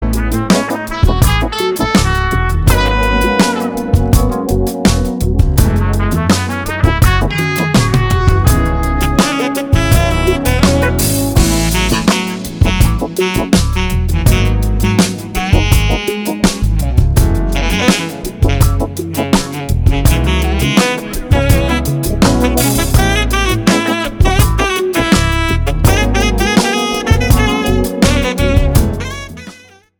EASY LISTENING  (01.58)